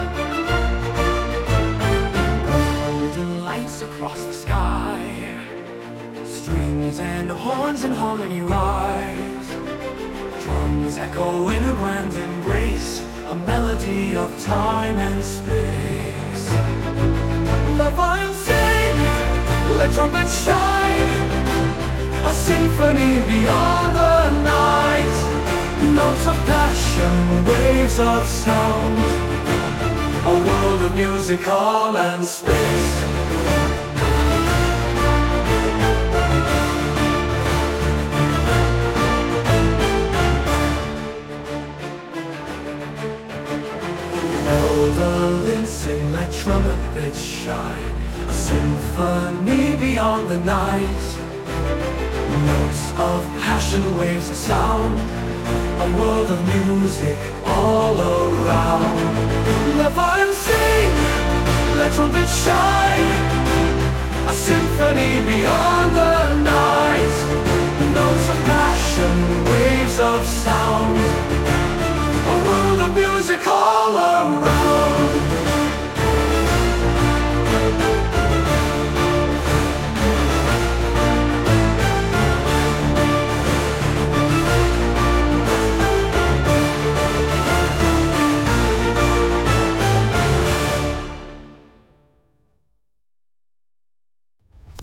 8. How to Create a Full Orchestra Song on Suno?
Suno AI can generate orchestral music based on lyrics and descriptions.